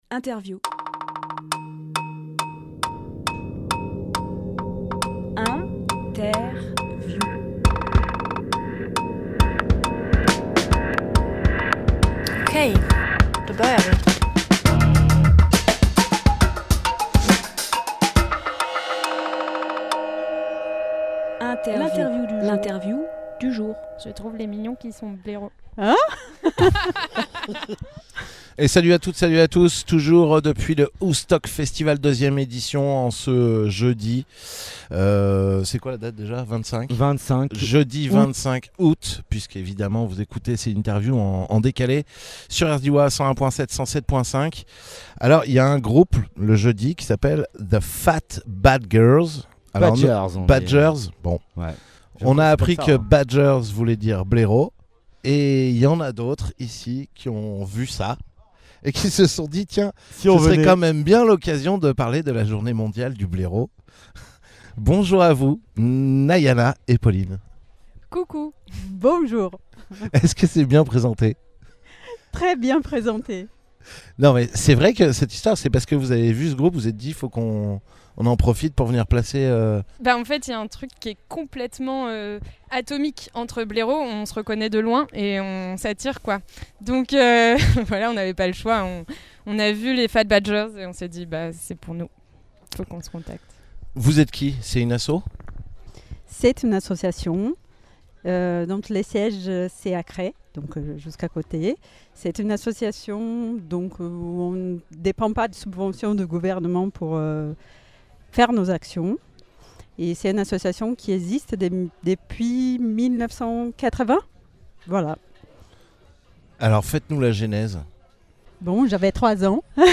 Emission - Interview ASPAS : Journée Mondiale Des Blaireaux Publié le 31 août 2022 Partager sur… Télécharger en MP3 Une interview surprise pour une cause qui nous a surprise ! Sur le Aoustock Festival #2, sautant sur l’occasion de la venue du groupe The Fat Badgers, de joyeuses personnes ont posé un stand pour la Journée Mondiale Des Blaireaux ! Une action menée par l’ASPAS et dont le but est de sensibiliser le public sur les pratiques de la chasse destruction des blaireaux, particulièrement cruelle et affligeante.